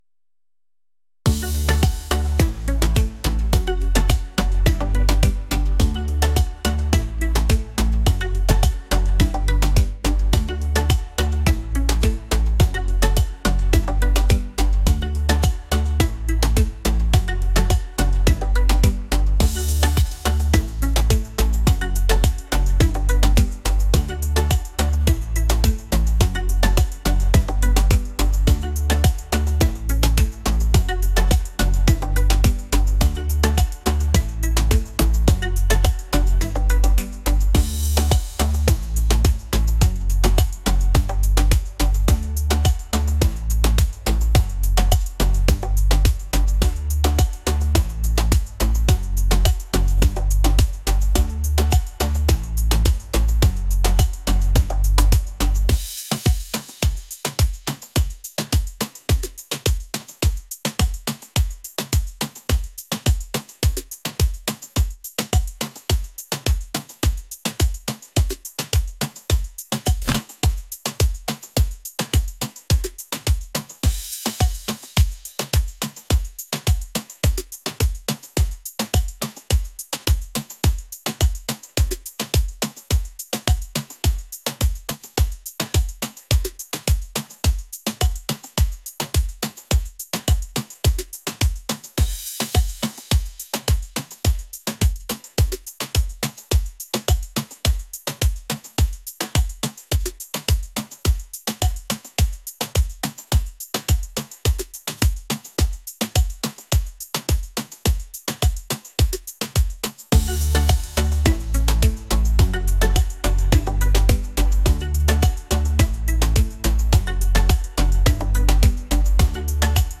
pop | lively